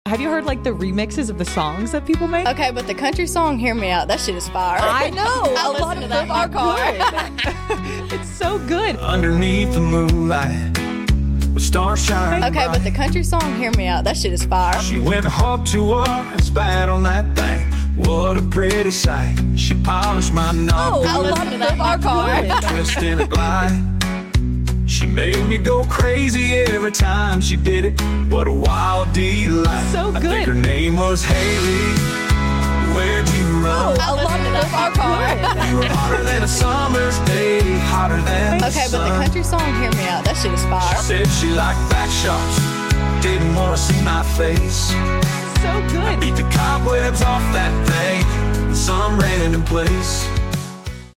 AI Country song